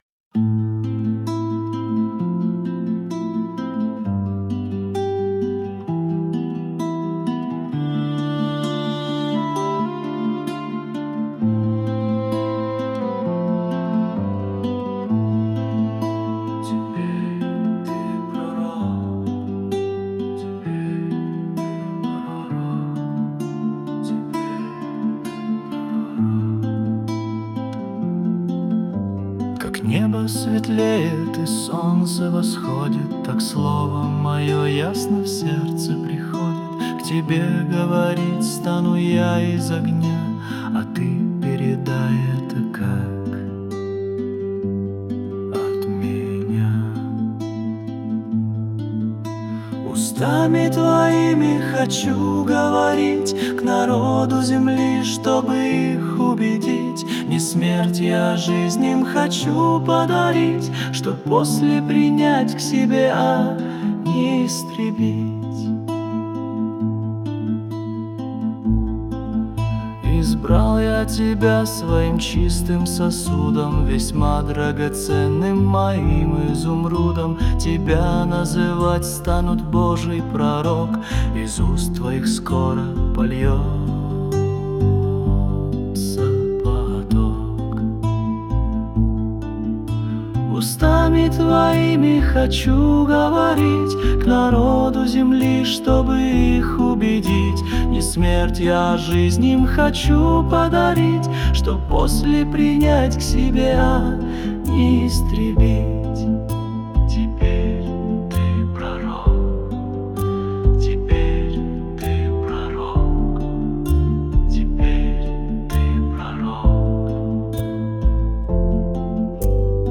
161 просмотр 1193 прослушивания 52 скачивания BPM: 65